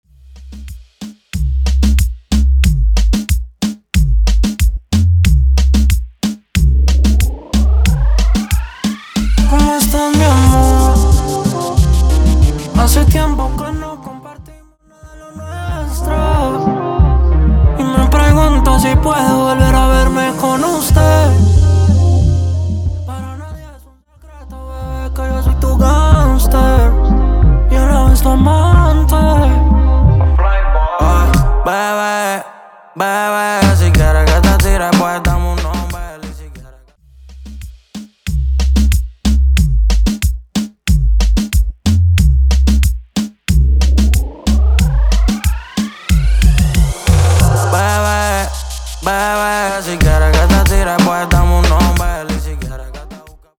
Intro Dirty, Coro Dirty